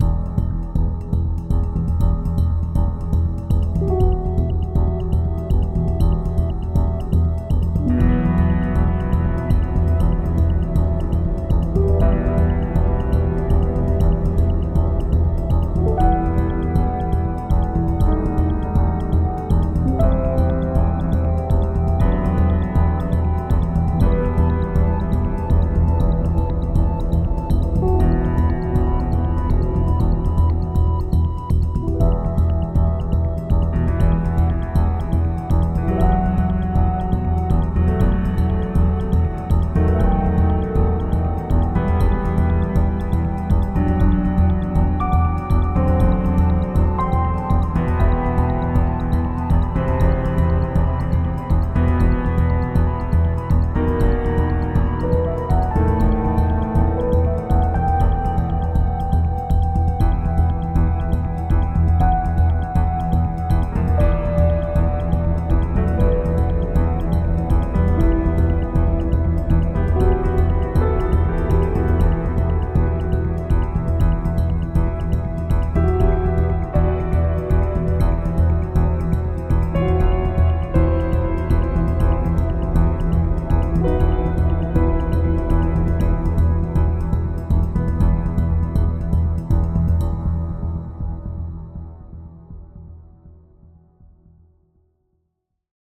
Added Ambient music pack.